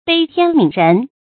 悲天憫人 注音： ㄅㄟ ㄊㄧㄢ ㄇㄧㄣˇ ㄖㄣˊ 讀音讀法： 意思解釋： 悲天：悲嘆時世；憫：哀憐；憫人：憐憫眾人。